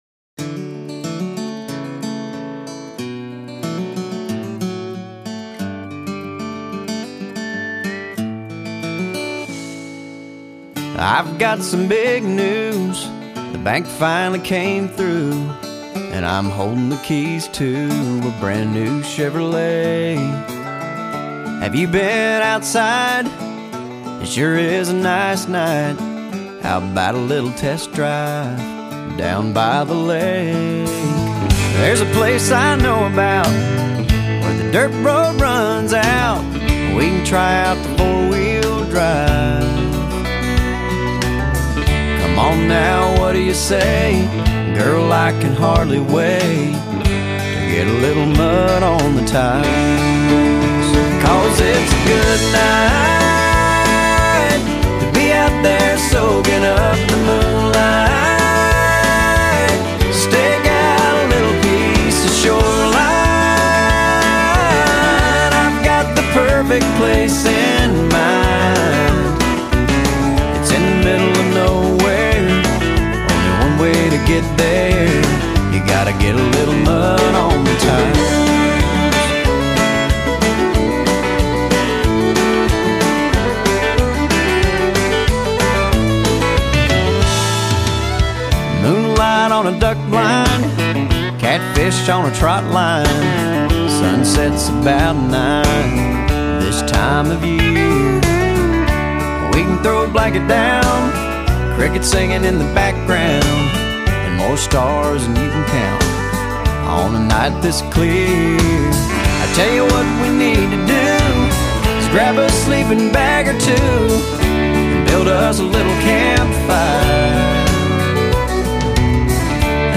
音乐风格：Western Swing Revival（西部摇摆复兴），
Neo-Traditionalist Country（新传统主义乡村），
Contemporary Country（当代乡村）